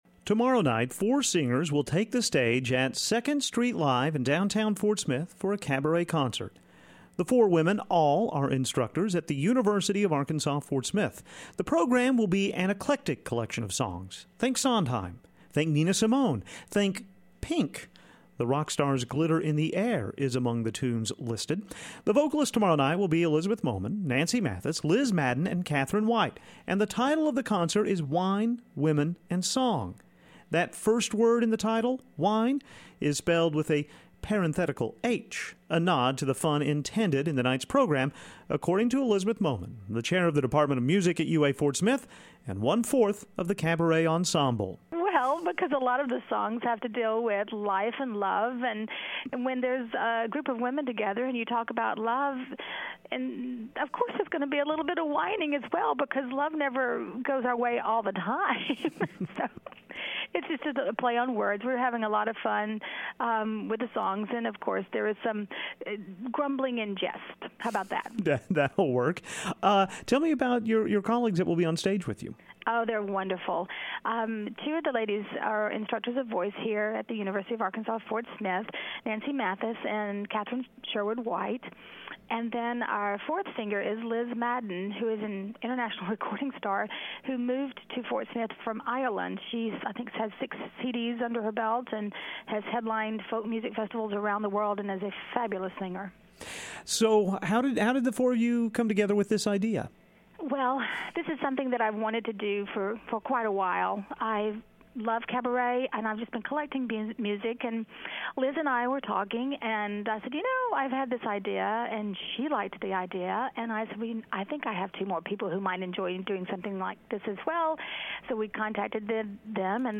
Cabaret.mp3